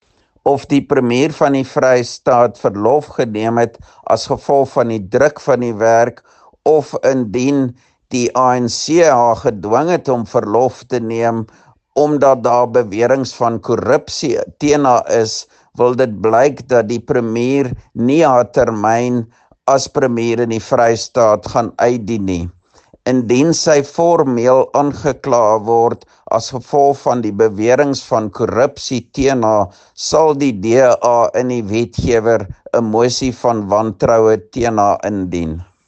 Afrikaans soundbites by Roy Jankielsohn MPL and Sesotho soundbite by Jafta Mokoena MPL